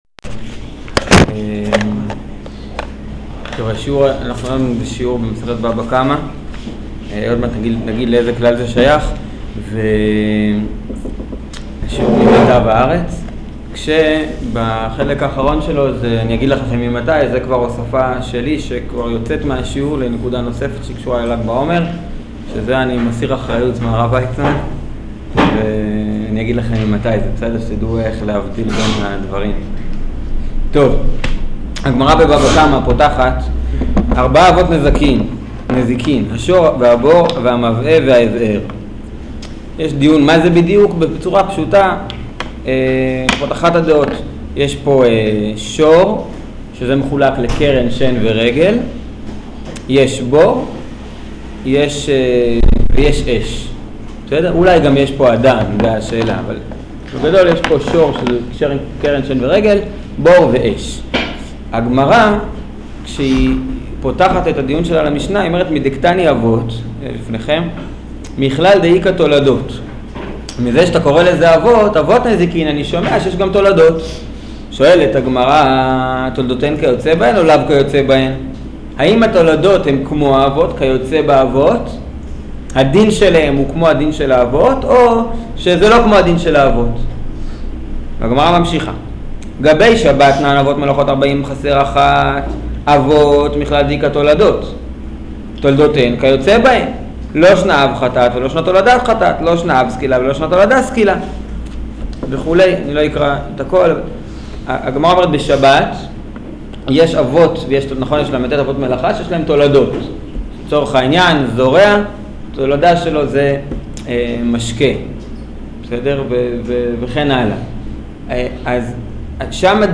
שיעור לקראת ל"ג בעומר